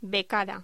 Locución: Becada